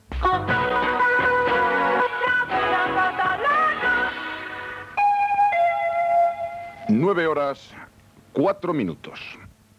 Indicatiu de l'emissora i hora.